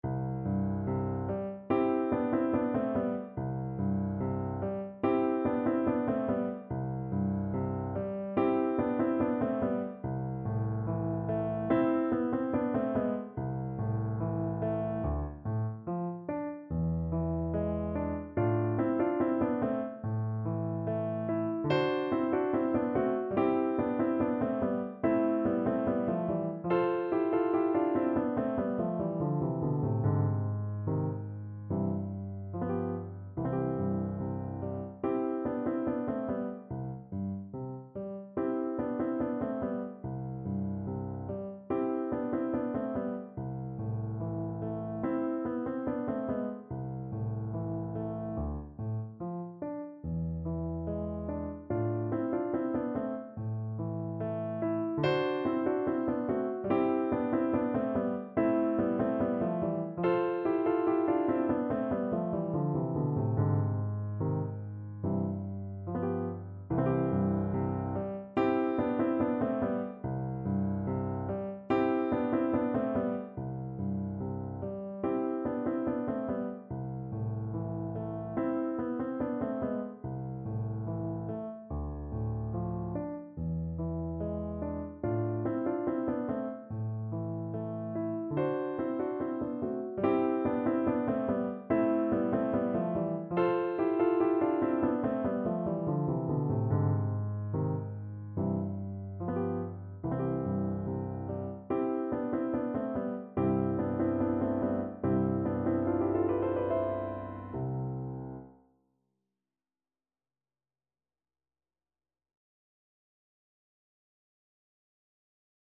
Voice
C major (Sounding Pitch) (View more C major Music for Voice )
4/4 (View more 4/4 Music)
Allegretto (=72)
Classical (View more Classical Voice Music)